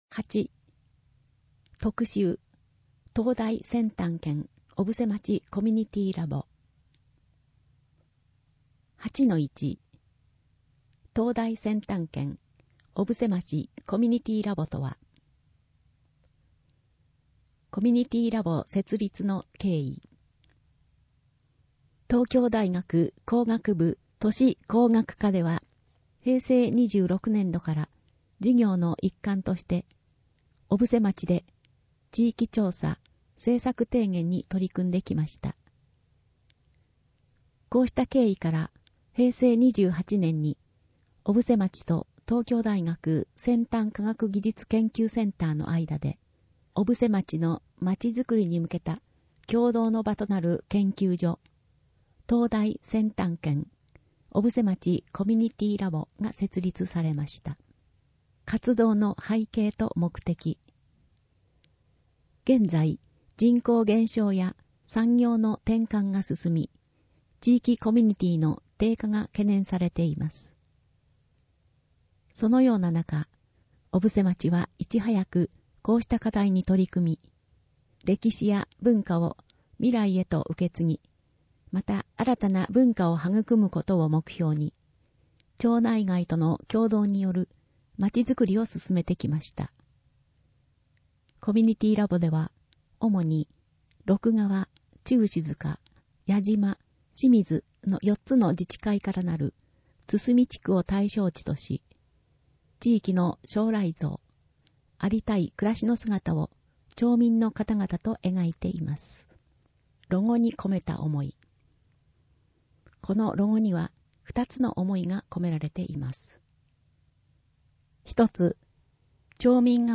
毎月発行している小布施町の広報紙「町報おぶせ」の記事を、音声でお伝えする（音訳）サービスを行っています。音訳は、ボランティアグループ そよ風の会の皆さんです。